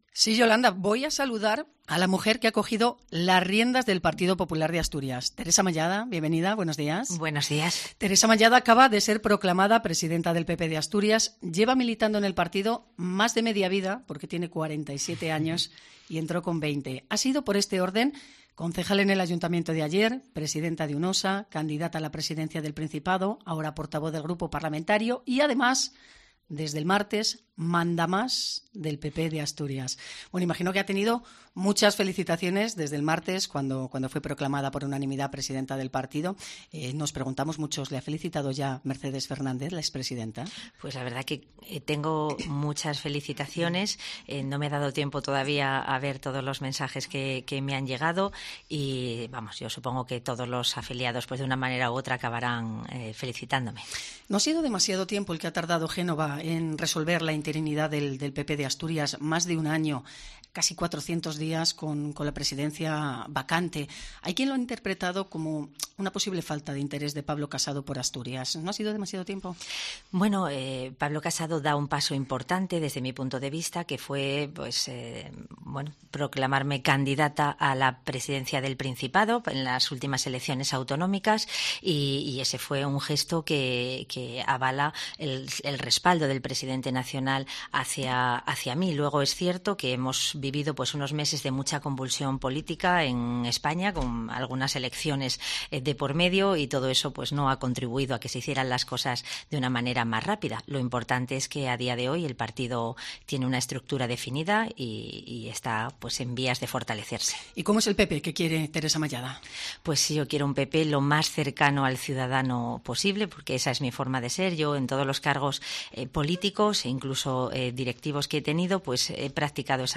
Entrevista a Teresa Mallada en COPE Asturias